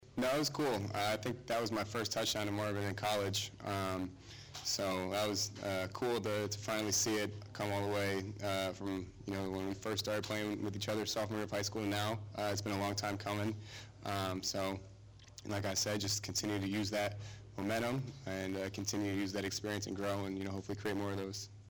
AUDIO EXCERPTS FROM KYLE McCORD PRESS CONFERENCE